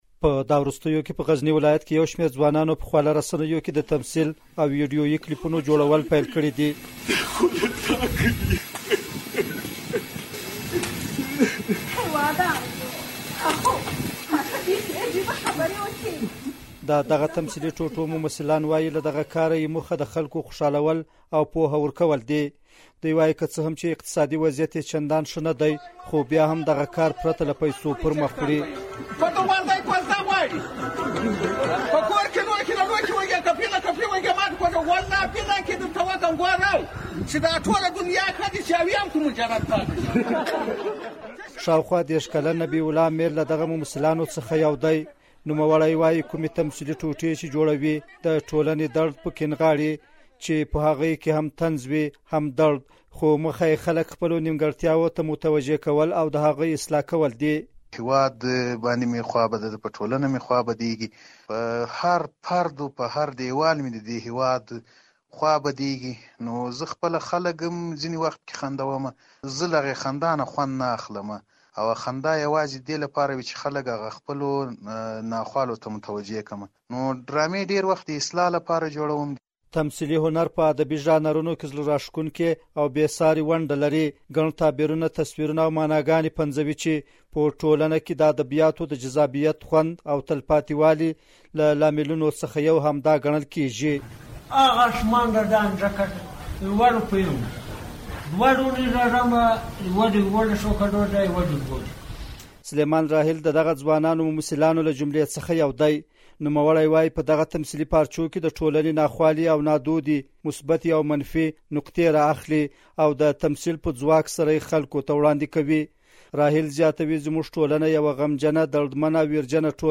د غزني راپور